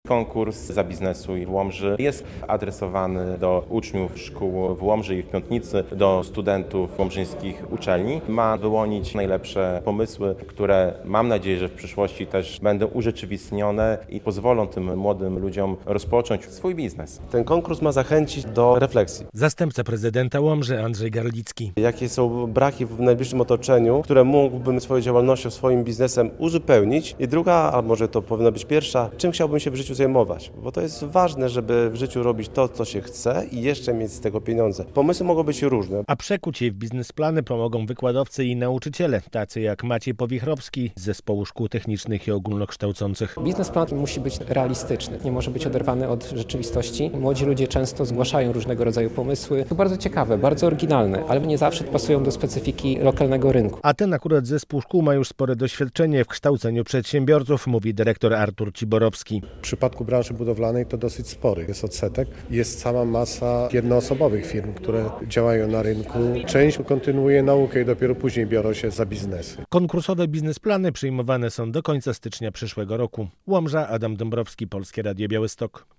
- Warunkiem przystąpienia do rywalizacji jest złożenie biznesplanu na rozpoczęcie działalności w Łomży, a kapituła konkursu wybierze najlepsze pomysły – mówi prezydent Łomży Mariusz Chrzanowski.
- Udział w tym konkursie skłonić ma uczestników do dwóch refleksji: czego brakuje w najbliższym otoczeniu i mógłbym to wypełnić własną działalnością oraz co chciałbym w życiu robić – mówi zastępca prezydenta miasta Andrzej Garlicki.